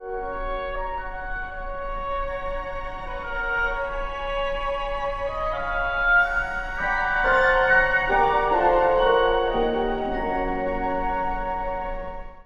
浮遊感のある独特のテーマが流れます。